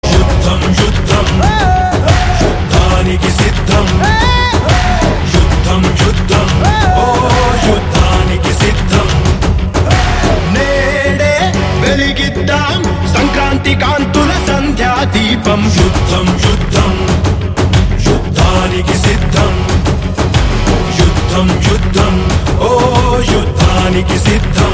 best flute ringtone download | love song ringtone
mass ringtone download